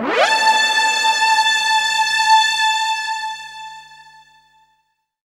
strTTE65022string-A.wav